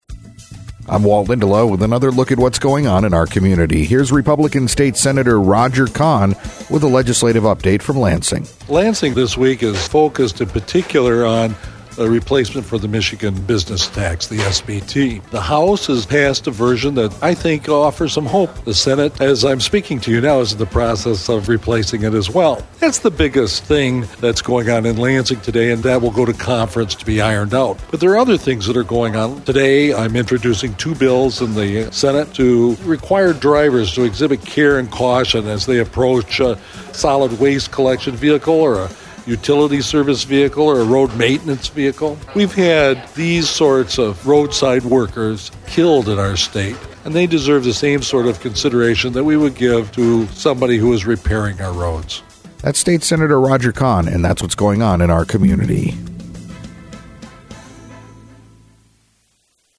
INTERVIEW: State Senator Roger Kahn